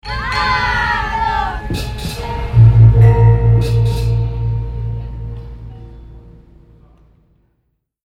Alle Sounds gibt es in 2 Formaten, im RealAudio-Format in ausreichender Qualität und im MP3-Format in sehr guter Qualität.
1 Willkommensound kurz